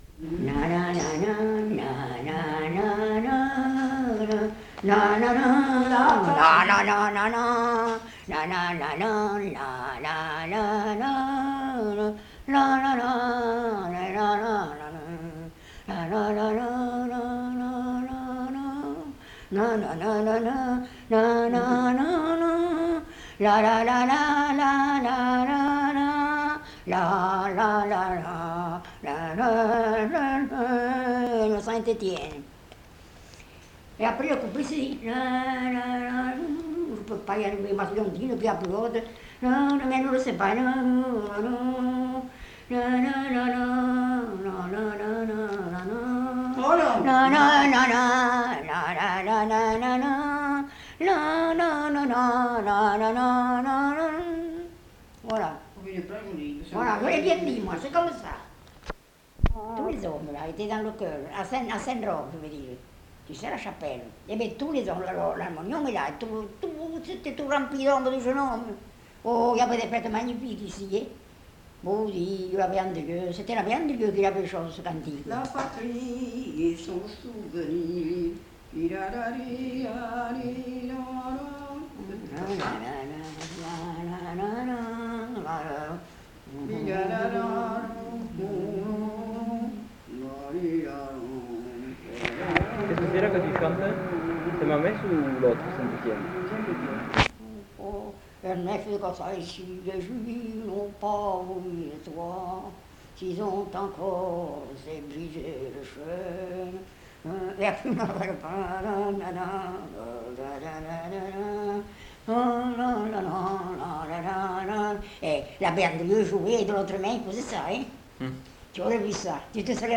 Aire culturelle : Cabardès
Lieu : Mas-Cabardès
Genre : chant
Effectif : 1
Type de voix : voix de femme
Production du son : fredonné